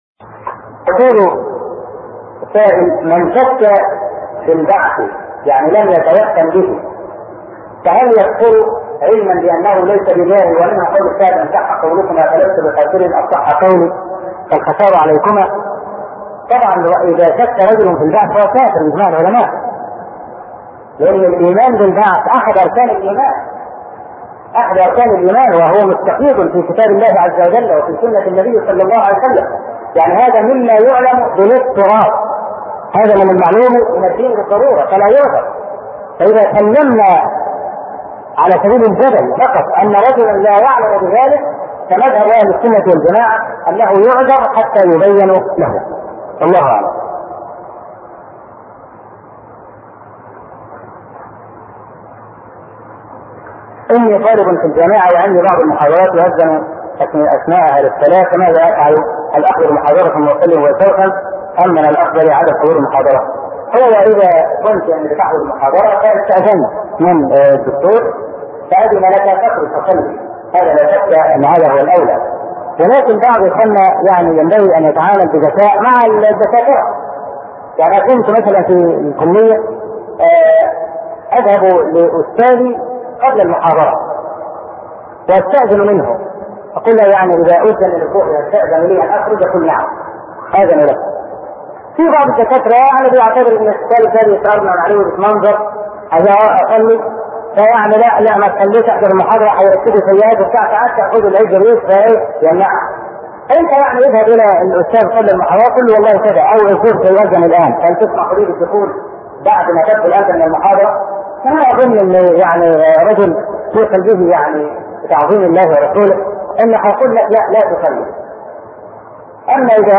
أرشيف الإسلام - ~ أرشيف صوتي لدروس وخطب ومحاضرات الشيخ أبو إسحاق الحويني